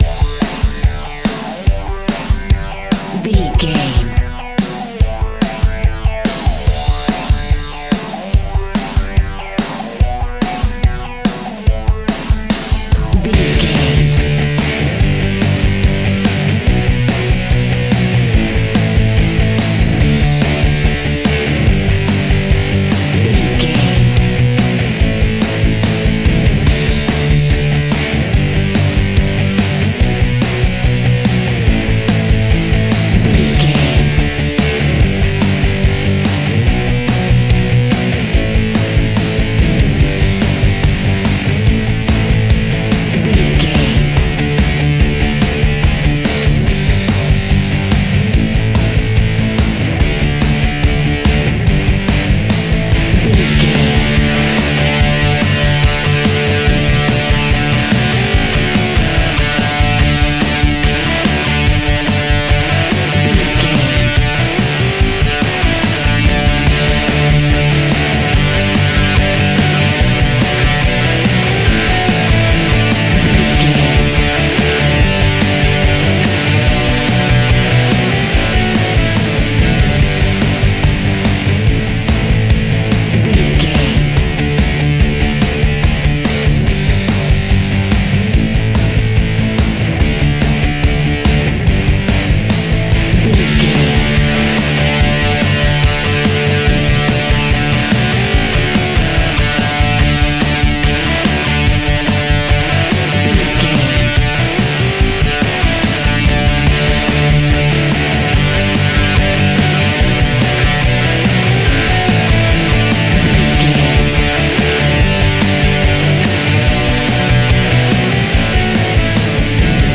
Epic / Action
Fast paced
Ionian/Major
D
guitars
hard rock
distortion
instrumentals
Rock Bass
heavy drums
distorted guitars
hammond organ